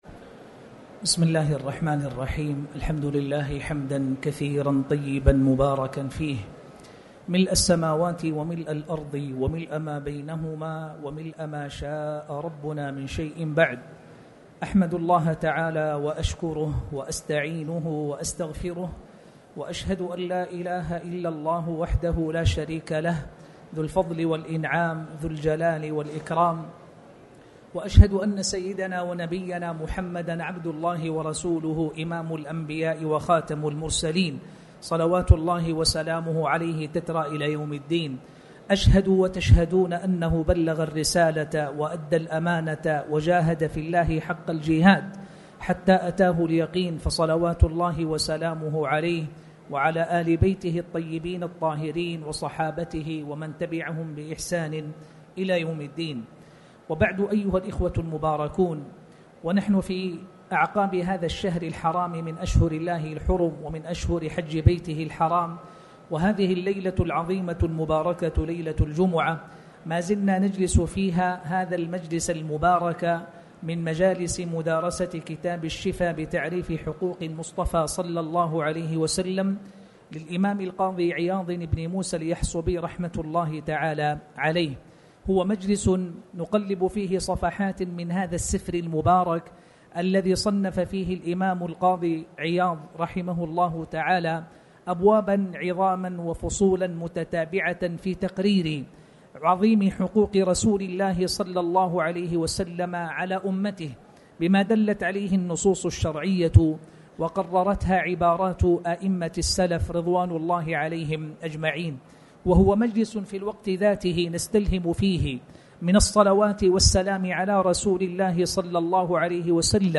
تاريخ النشر ٢٠ ذو القعدة ١٤٣٩ هـ المكان: المسجد الحرام الشيخ